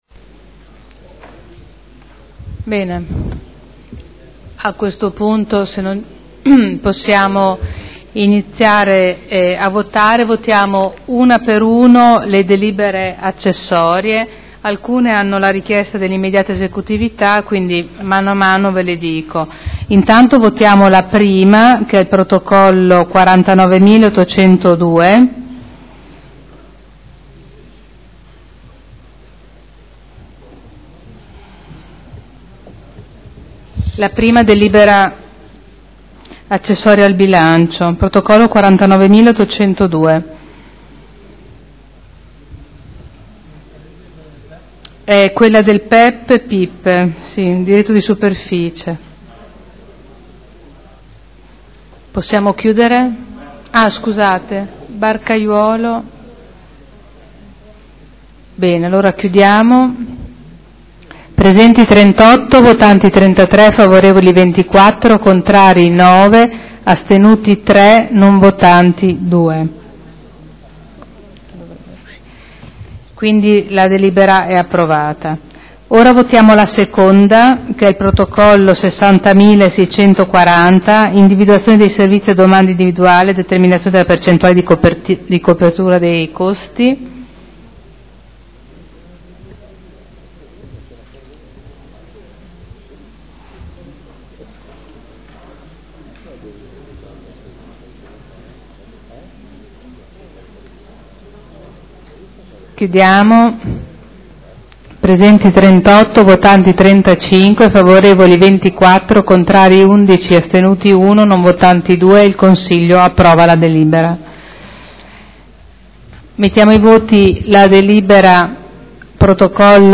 Seduta dell'11 giugno Votazioni su delibere accessorie, bilancio e ordini del giorno